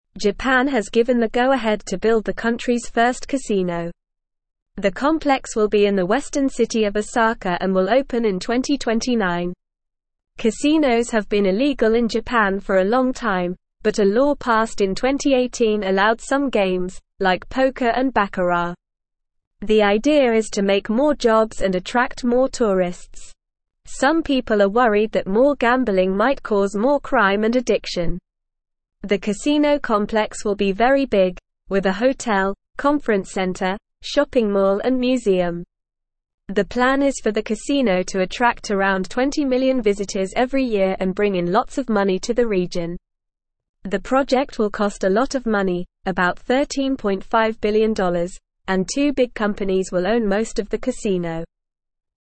Normal
English-Newsroom-Beginner-NORMAL-Reading-Japan-Building-First-Casino-to-Attract-Tourists.mp3